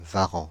Ääntäminen
France (Île-de-France): IPA: /va.ʁɑ̃/